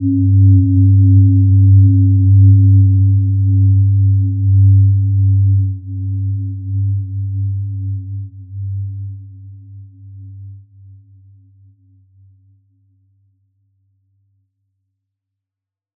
Gentle-Metallic-4-G2-p.wav